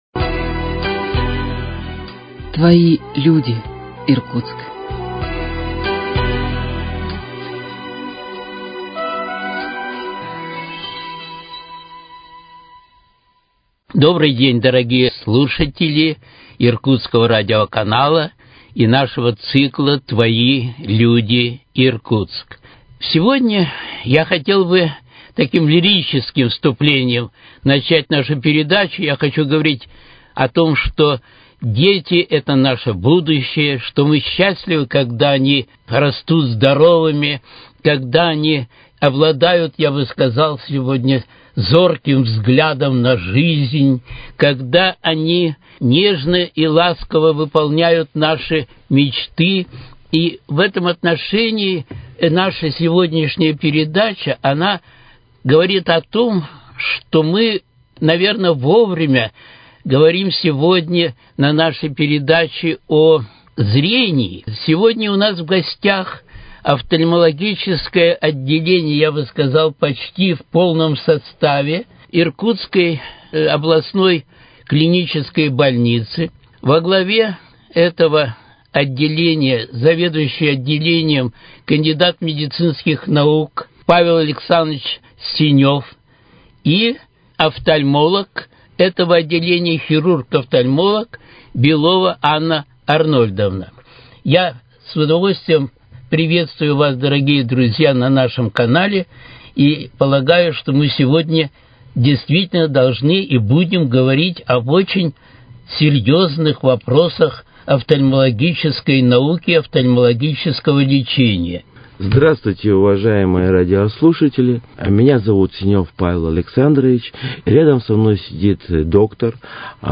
Твои люди, Иркутск: Беседа